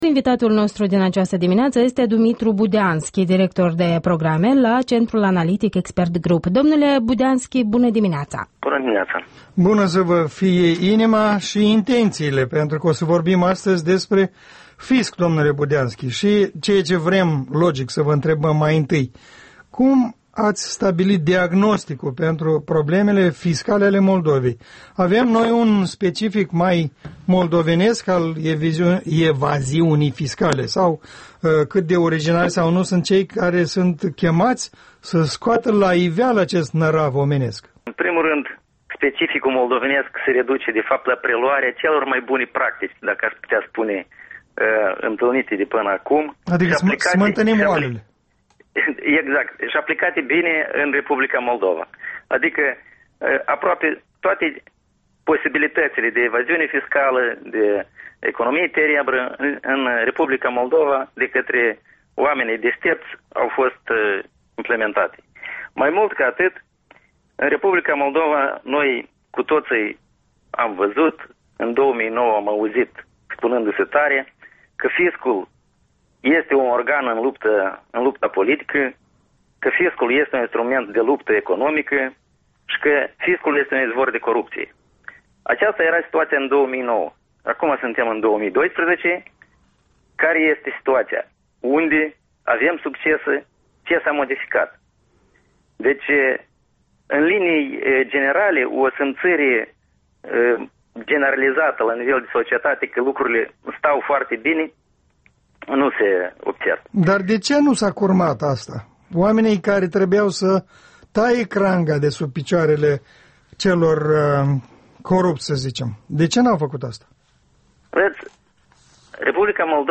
Interviul dimineții la EL